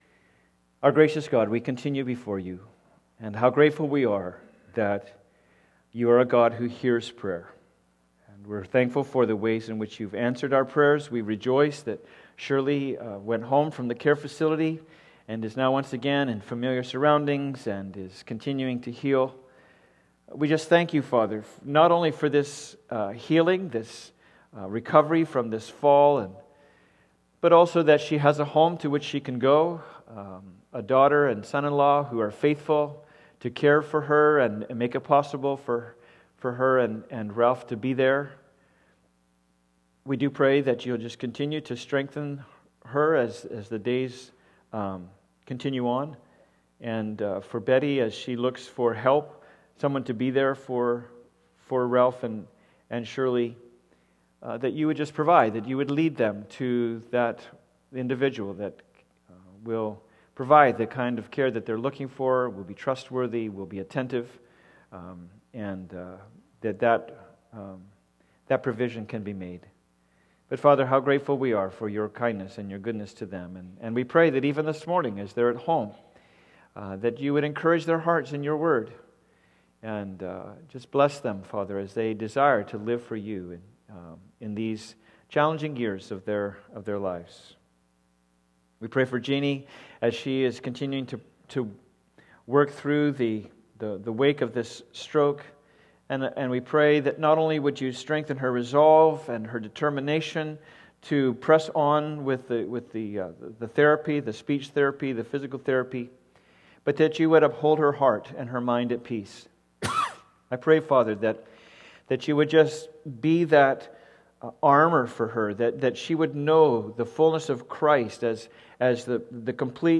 Passage: Mark 9:1-8 Service Type: Sunday Service